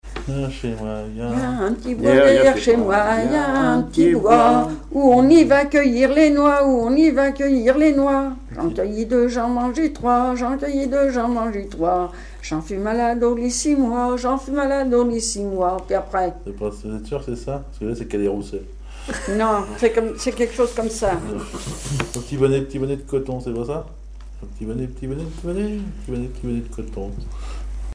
Mémoires et Patrimoines vivants - RaddO est une base de données d'archives iconographiques et sonores.
Genre laisse
Chansons et commentaires
Pièce musicale inédite